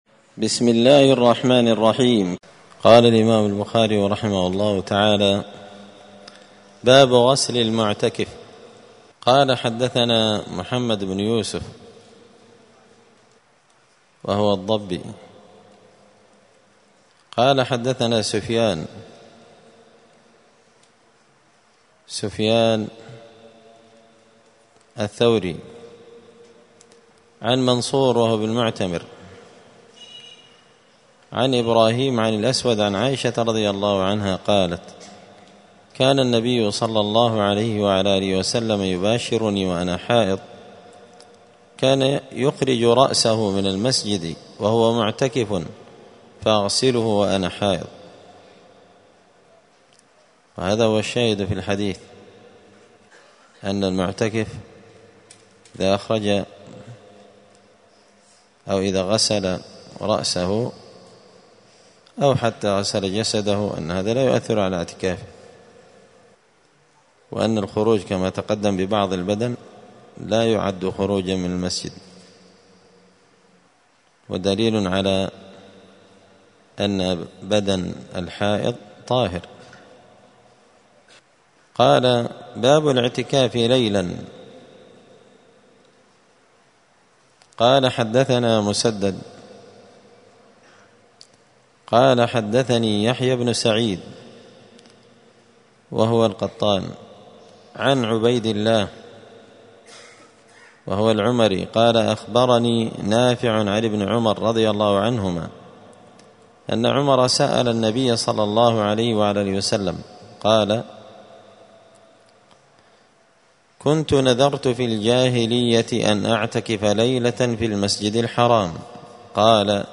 دار الحديث السلفية بمسجد الفرقان قشن المهرة اليمن